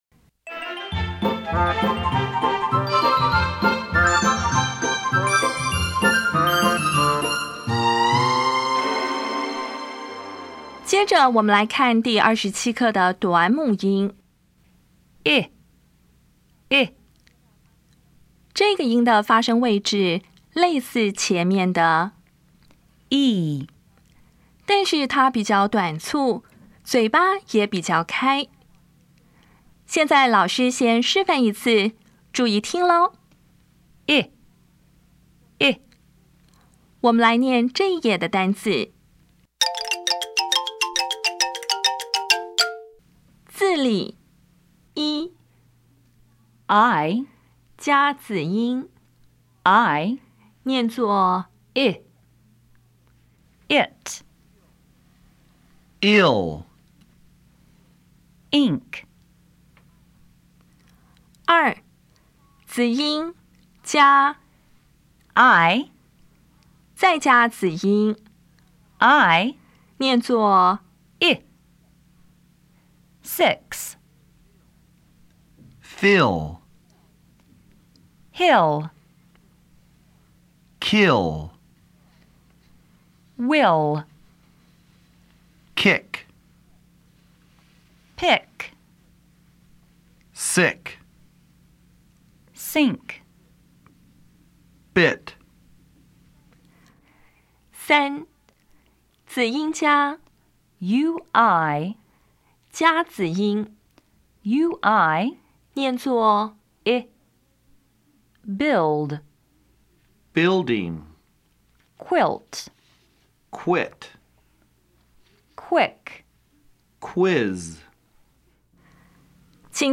当前位置：Home 英语教材 KK 音标发音 母音部分-1: 短母音 [ɪ]
音标讲解第二十七课
比较[i][ɪ]       [i](长音) [ɪ](短音)
Listening Test 12